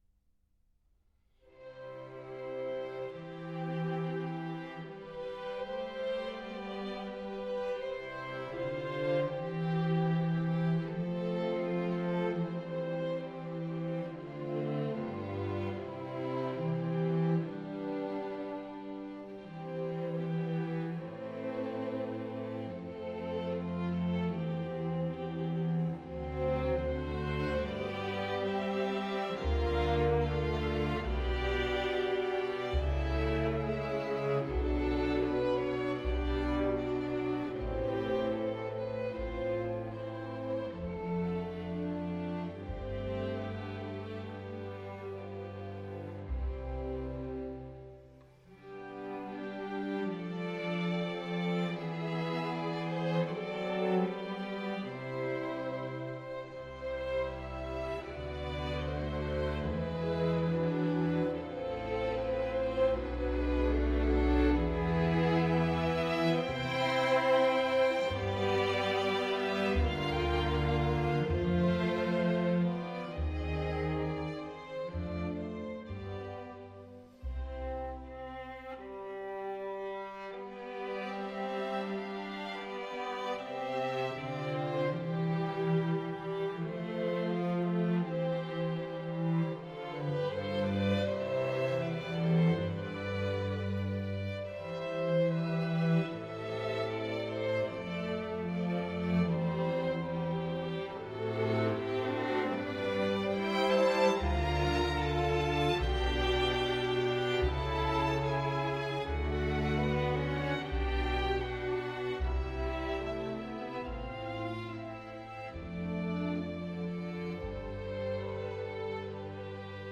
一帖良药，这张专辑尽管不是标准的弦四，但就编制来说也十分的接近了
尽管他的生命带有些悲剧的色彩，但他的音乐当中却丝毫没有一点黑暗面。